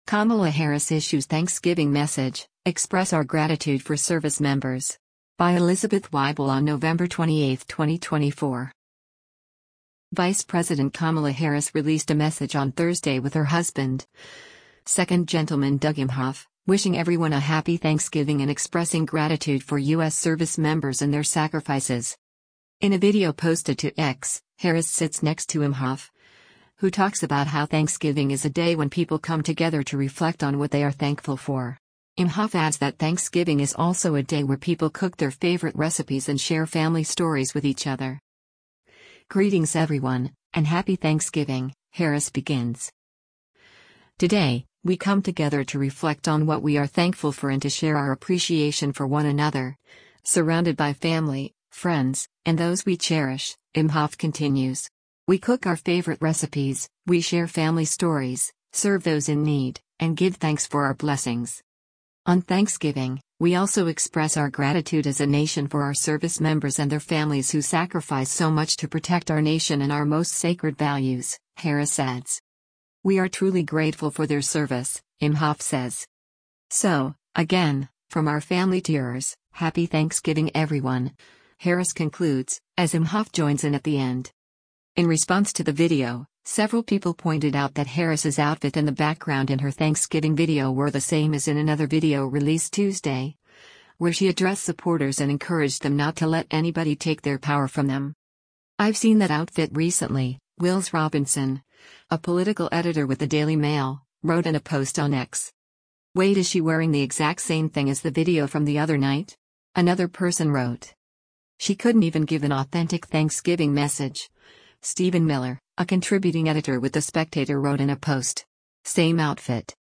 Vice President Kamala Harris released a message on Thursday with her husband, second gentleman Doug Emhoff, wishing everyone a “happy Thanksgiving” and expressing “gratitude” for U.S. service members and their sacrifices.
“So, again, from our family to yours, happy Thanksgiving everyone,” Harris concludes, as Emhoff joins in at the end.